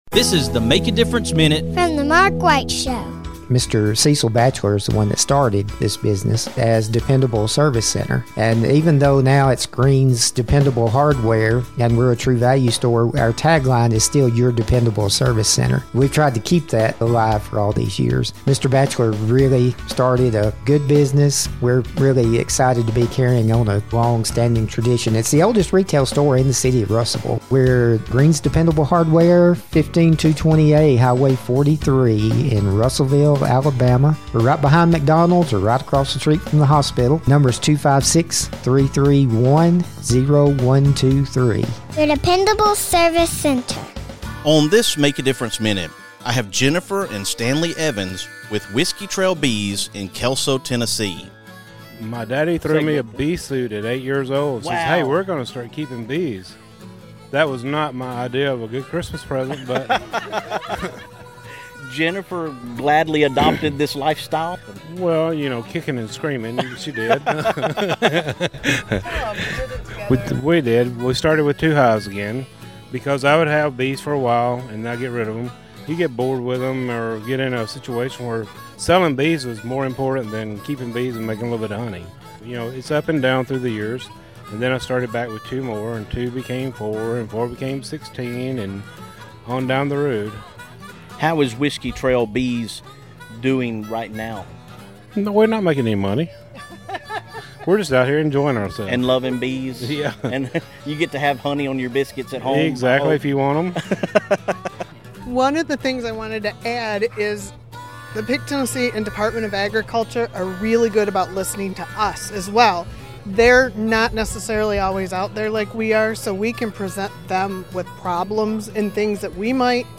On this MADM, I am coming to you from Rory Feek's Homestead Festival in Columbia, Tennessee!